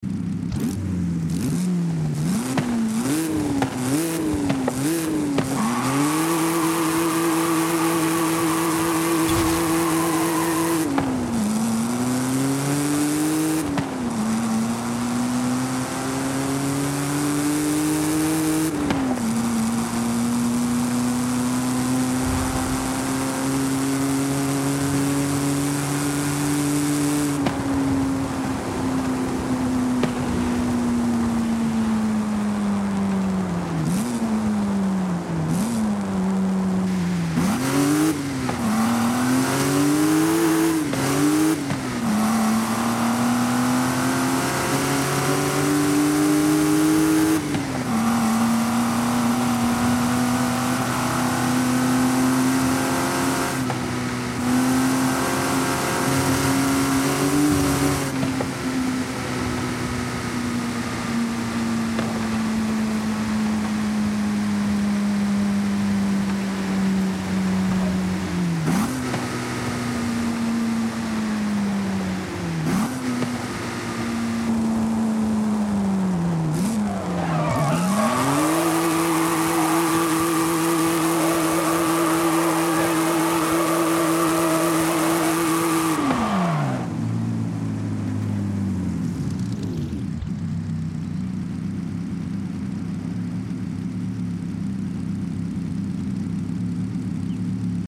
Maserati 8CTF 1939 Exhaust Sound sound effects free download